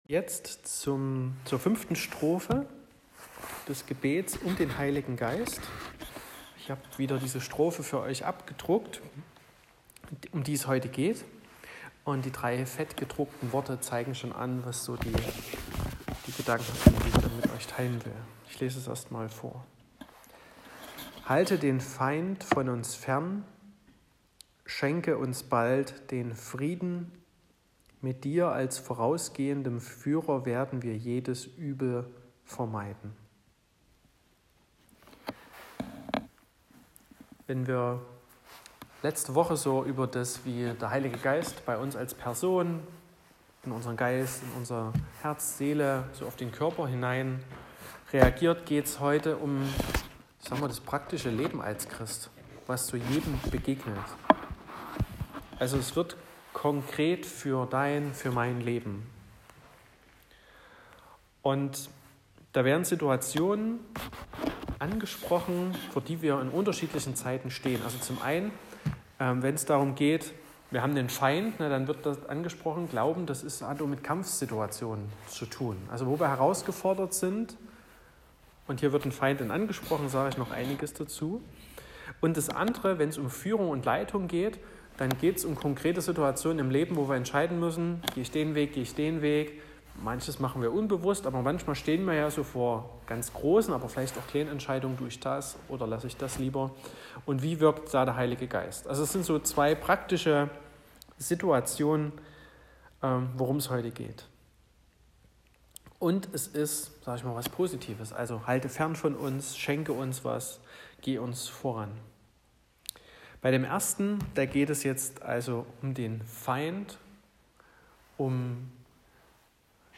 01.06.2022 – Stille und Gebet vor Pfingsten
Predigt und Aufzeichnungen